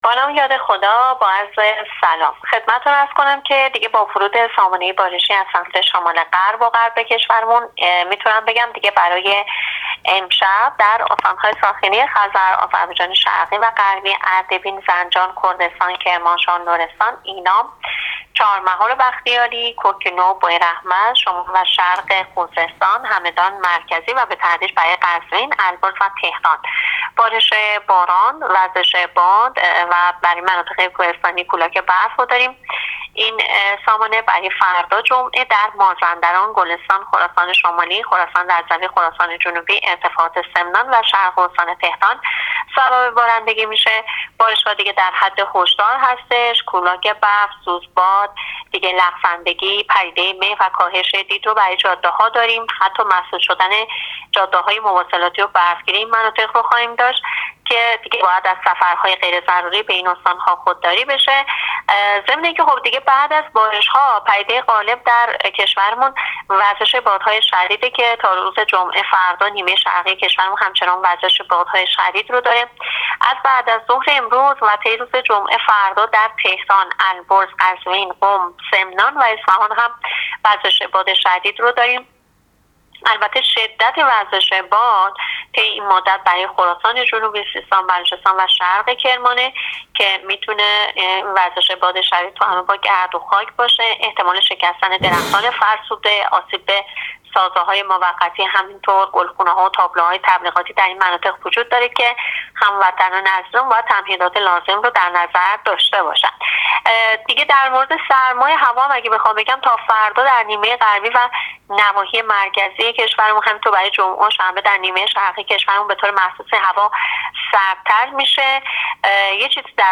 کارشناس سازمان هواشناسی کشور در گفت‌وگو با رادیو اینترنتی پایگاه خبری وزارت راه‌ و شهرسازی، آخرین وضعیت آب‌و‌هوای کشور را تشریح کرد.
گزارش رادیو اینترنتی پایگاه خبری از آخرین وضعیت آب‌‌و‌‌‌هوای سی ام دی: